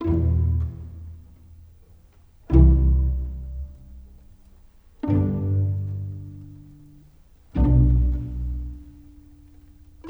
Rock-Pop 09 Pizzicato 01.wav